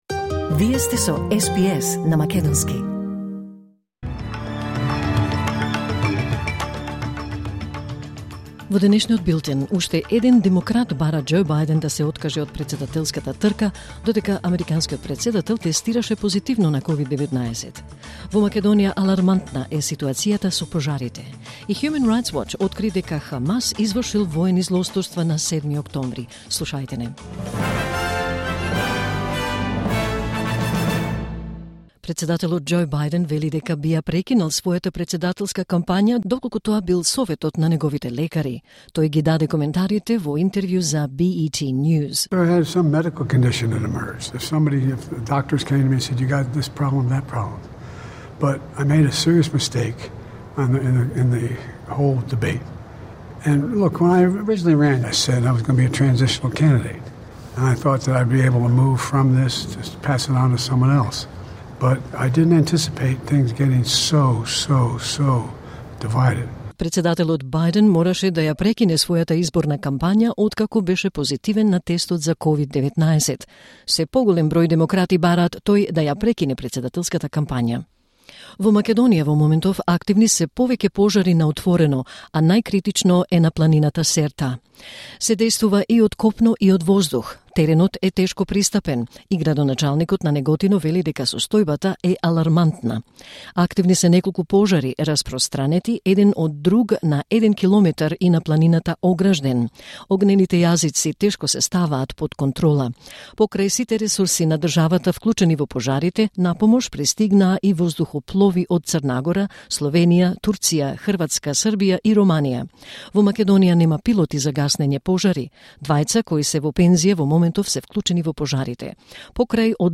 SBS News in Macedonian 18 July 2024